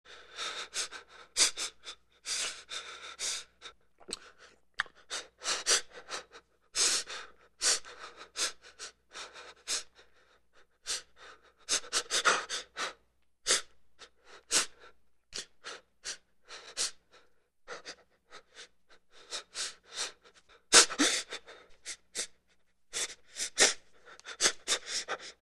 Звуки вздоха человека
Паническое дыхание